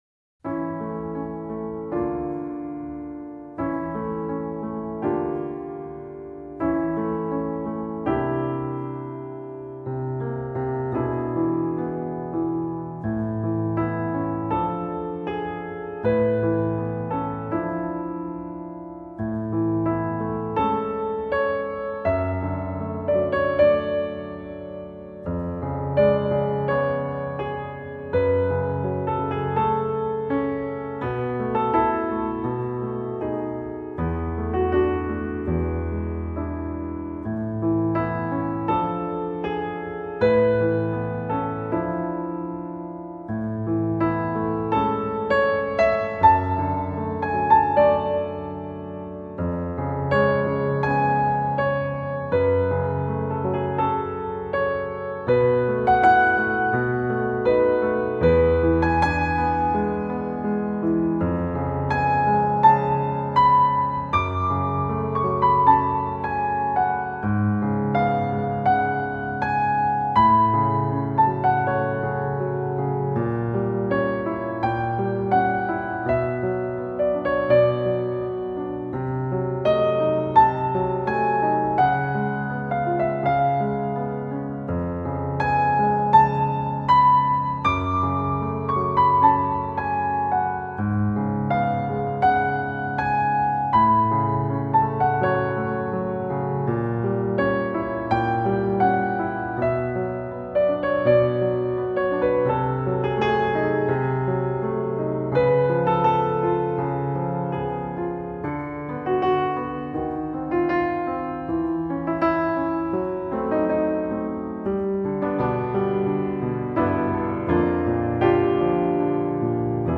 Лучший мировой инструментал